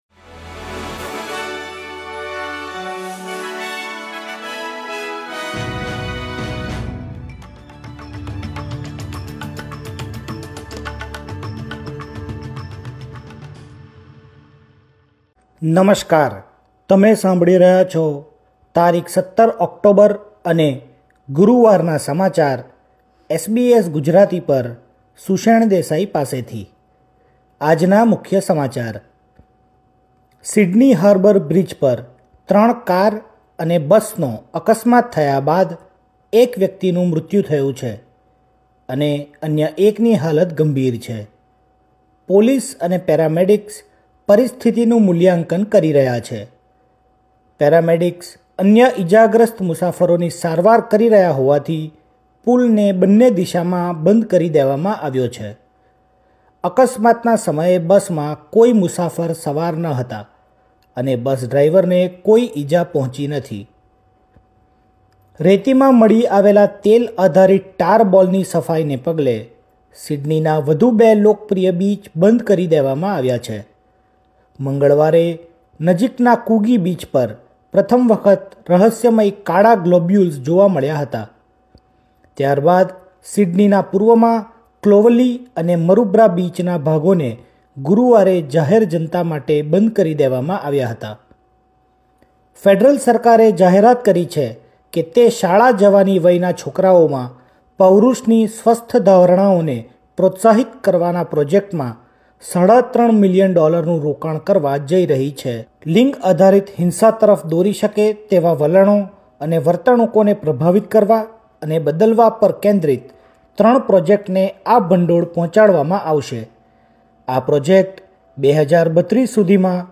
SBS Gujarati News Bulletin 17 October 2024